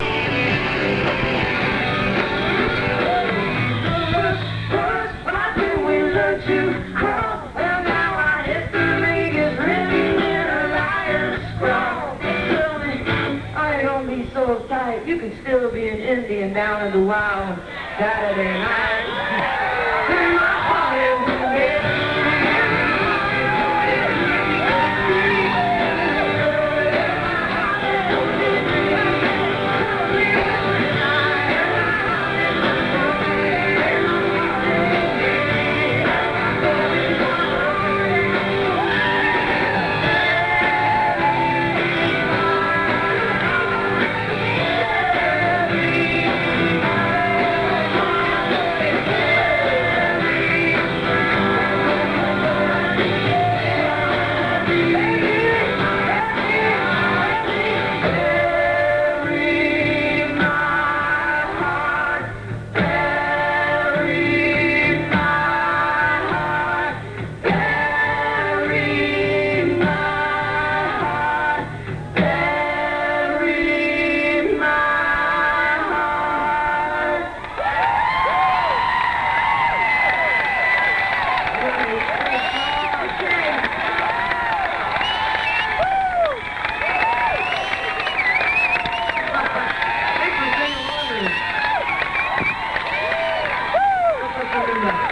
(incomplete, band show)